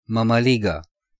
Mămăligă (Romanian pronunciation: [məməˈliɡə]
Ro-mămăligă.ogg.mp3